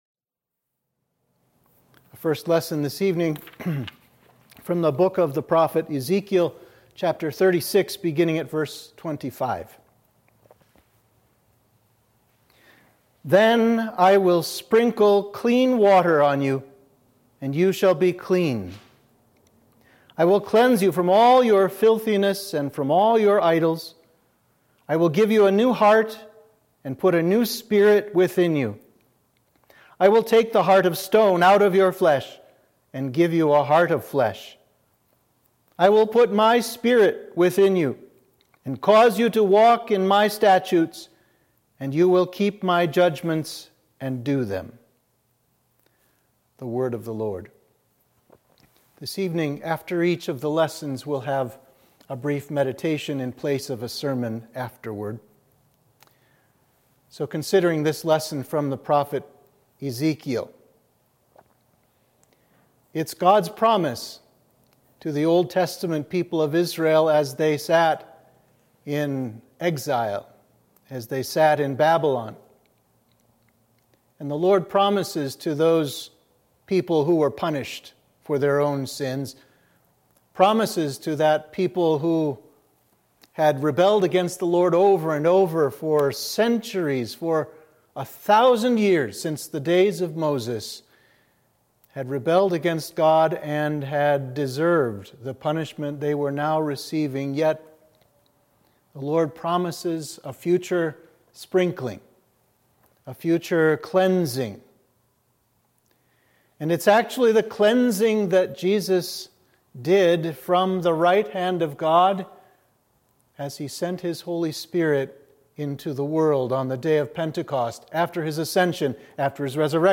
Sermon (audio)